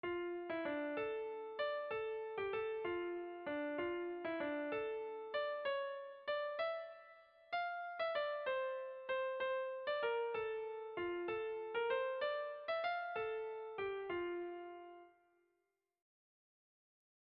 Erlijiozkoa
Lauko handia (hg) / Bi puntuko handia (ip)
AB